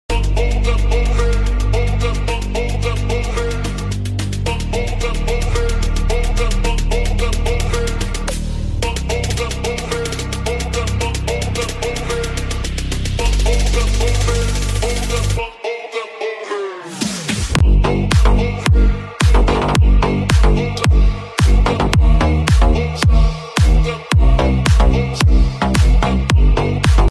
🎧😈The most powerful, enthusiastic music, sound effects free download